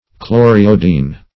Chloriodine \Chlor`i"o*dine\